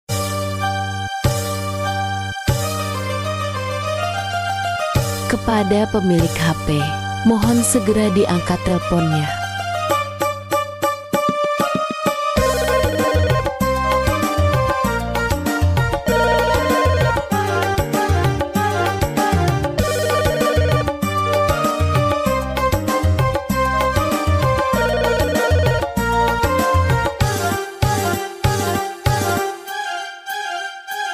Genre: Nada dering remix